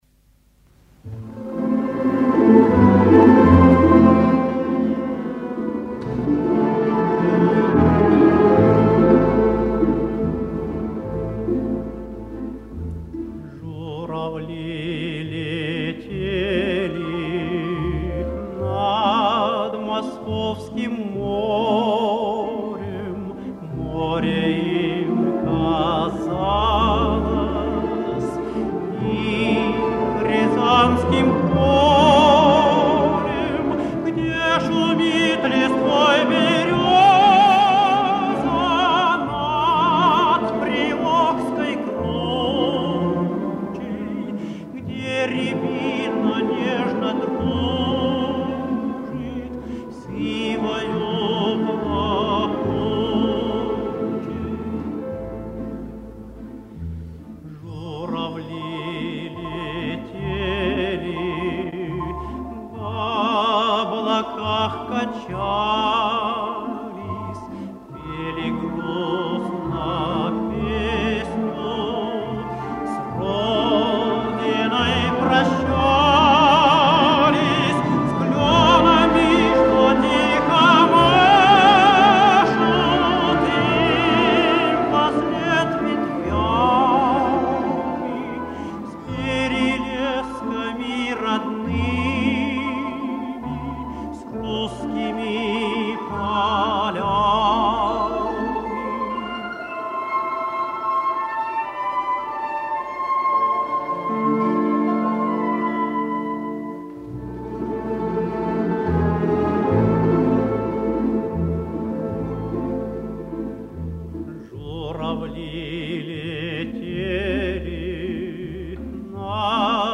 Очень красивая элегичная песня.